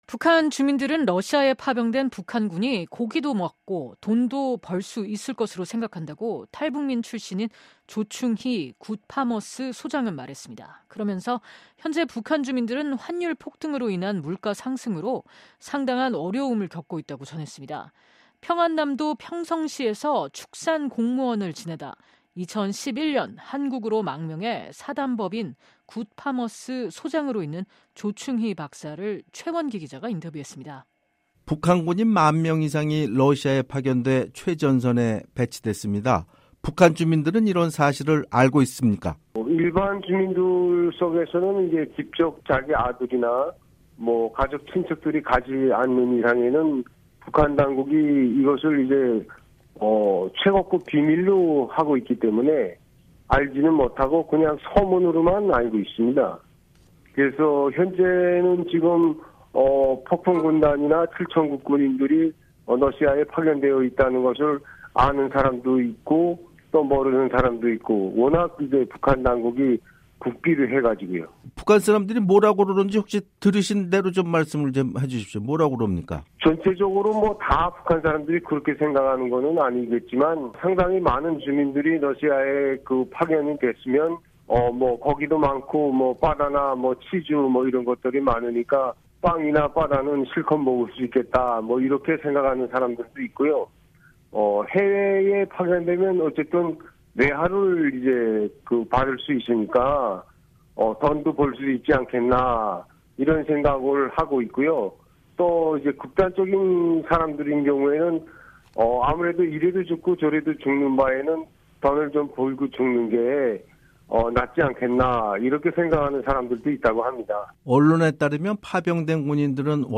[인터뷰] 북한 주민들 “파병된 북한군 고기도 먹고 돈도 벌어”